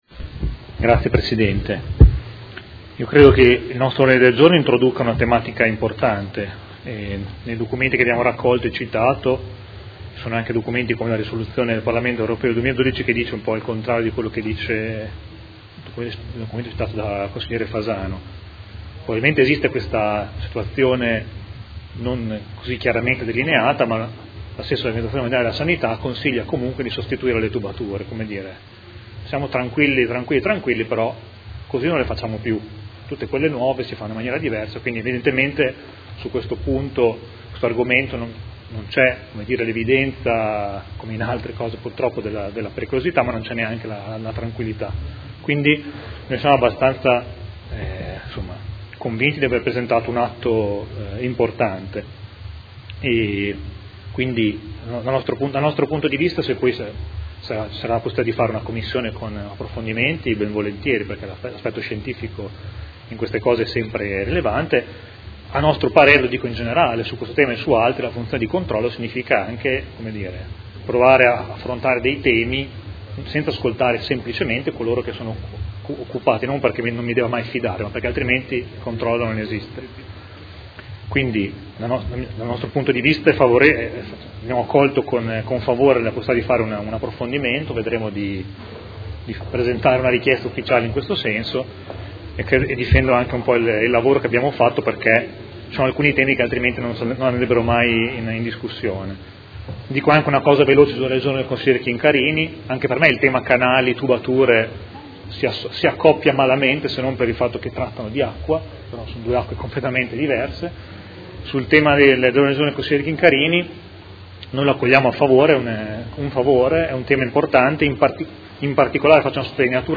Seduta del 27/10/2016 Dibattito su Mozione 106516 e Ordine de Giorno 157637